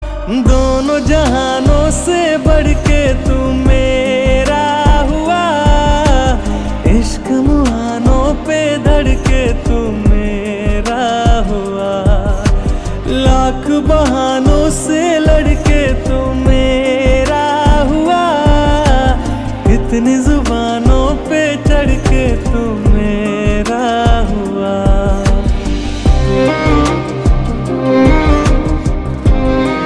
Hindi song